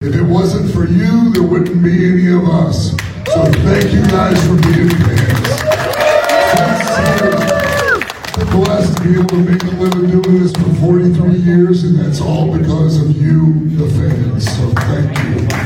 That was Al Snow, who also announced an all-women’s tournament, No Man’s Land, set for February 22, where a new NCCW Women’s Champion will be crowned.